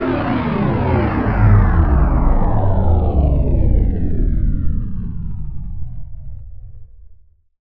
SCIFI_Down_05_mono.wav